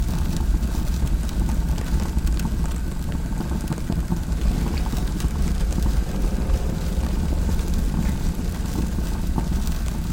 amb_small_fire_lp_01.mp3